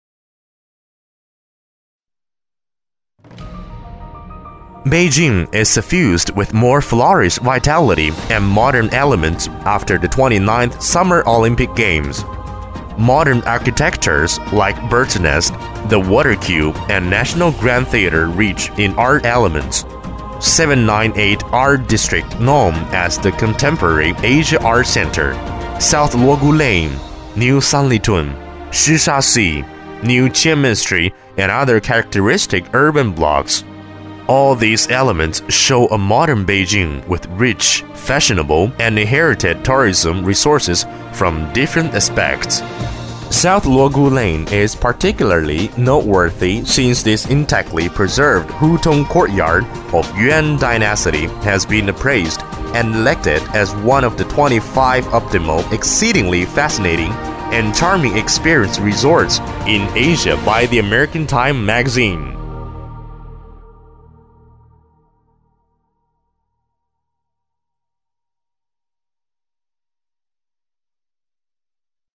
男40号配音师
十余年从业经验，精通中文，日文，英文，声音浑厚，庄重，大气。
英文-男40-北京 城市专题.mp3